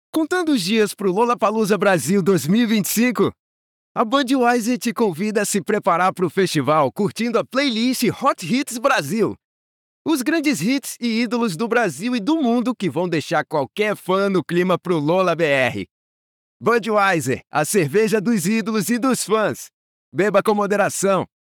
Home studio, doing this for a living since 2022, professional equipaments.
Brazilian Voiceover Talent.
Sprechprobe: Sonstiges (Muttersprache):